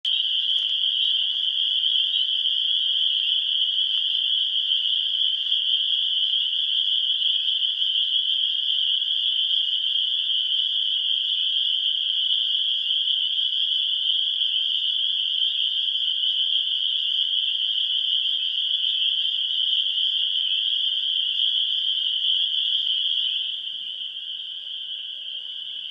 peepers-at-sunrise-landing.mp3